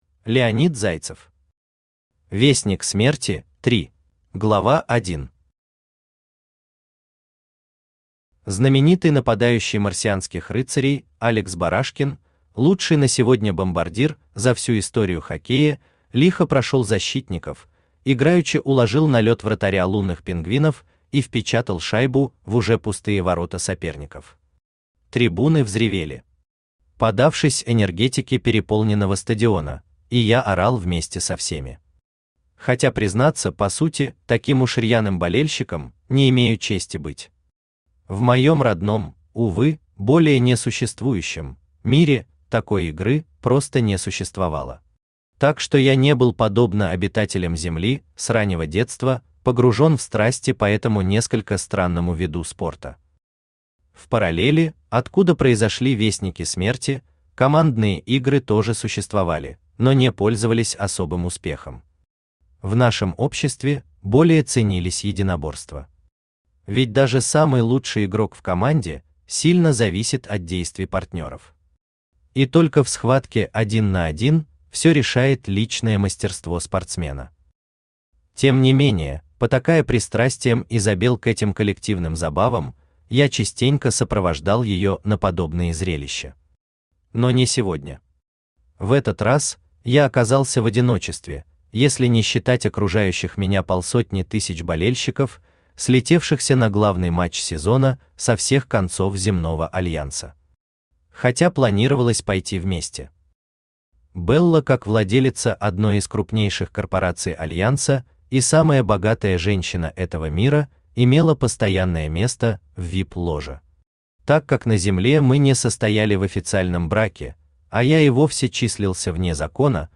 Аудиокнига Вестник смерти – 3 | Библиотека аудиокниг
Aудиокнига Вестник смерти – 3 Автор Леонид Зайцев Читает аудиокнигу Авточтец ЛитРес.